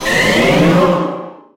Cri de Méga-Florizarre dans Pokémon HOME.
Cri_0003_Méga_HOME.ogg